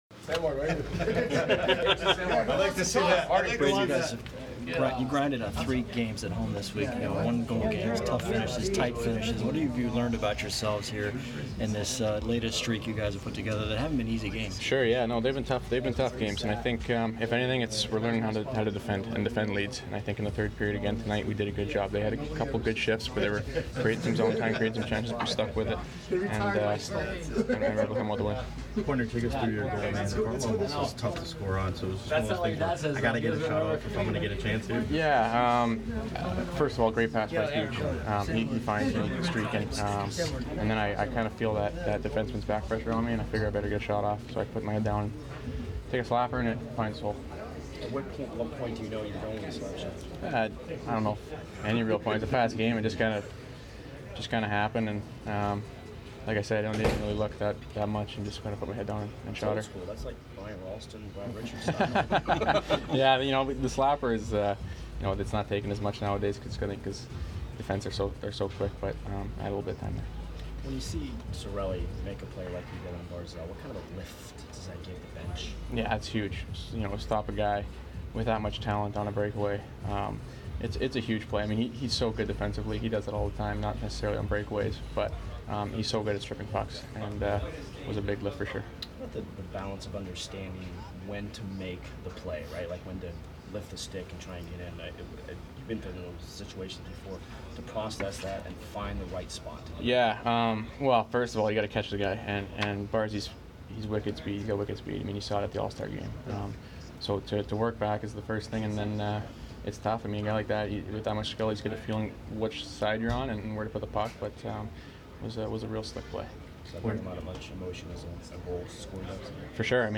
Brayden Point post-game 2/8